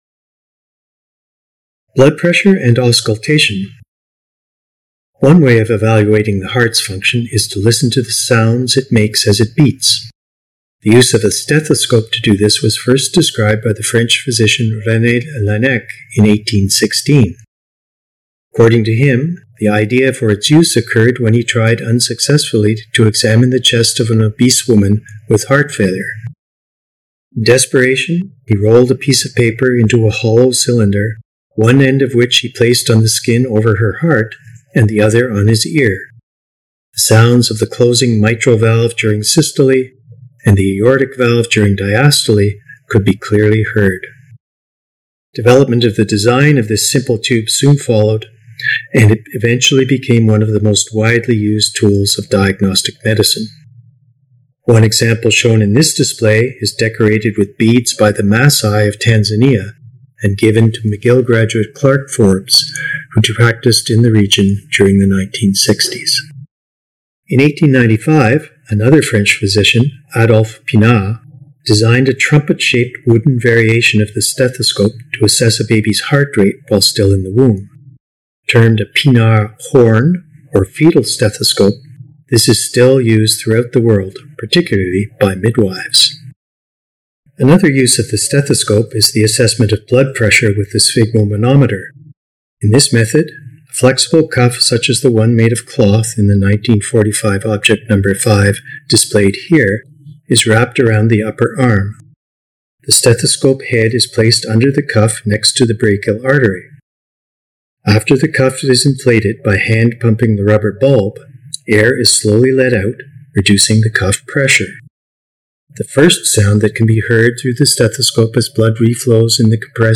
Below are audio guides for the artifacts displayed.